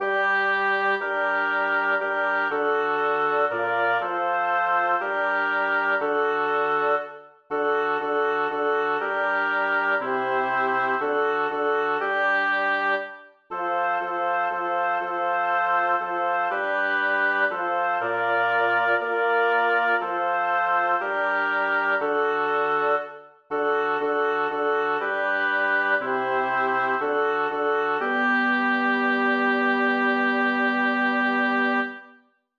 Title: Ich hab mein Sach Gott heim gestellt Composer: Melchior Vulpius Lyricist: Number of voices: 4vv Voicing: SATB Genre: Sacred, Chorale
Language: German Instruments: A cappella